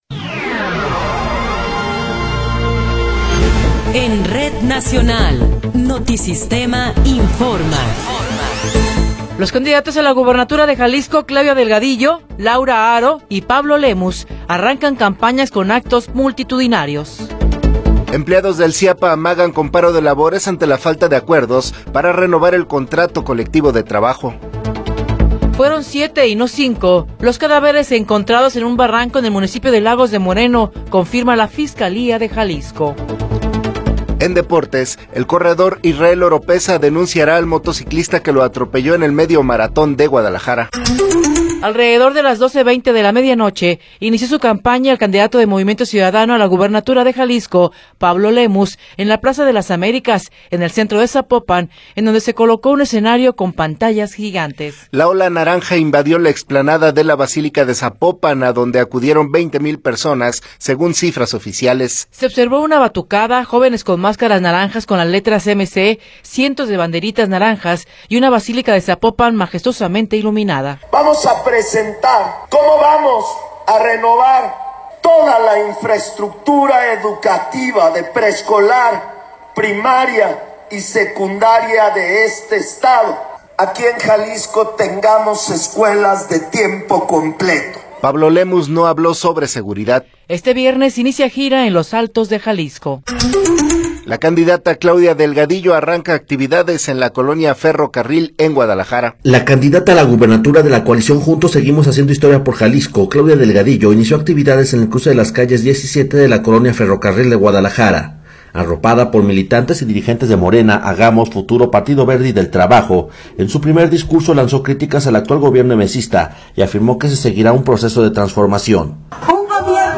Noticiero 9 hrs. – 1 de Marzo de 2024
Resumen informativo Notisistema, la mejor y más completa información cada hora en la hora.